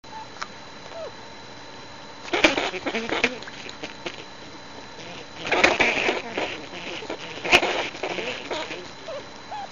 Грозный звук шиншиллы